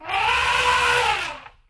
sounds_elephant_trumpet.ogg